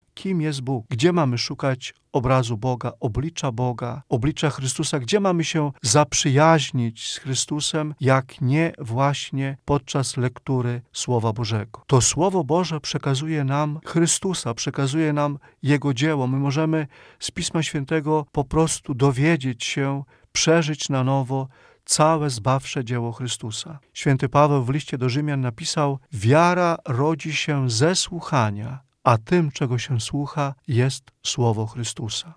biblista